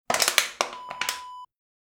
Aluminum Can Crush Wav Sound Effect #2
Description: The sound of a beer or soda can being crushed
Properties: 48.000 kHz 16-bit Stereo
A beep sound is embedded in the audio preview file but it is not present in the high resolution downloadable wav file.
Keywords: aluminum, beer, soda, can, crush, crushing, smash
aluminum-can-crush-preview-2.mp3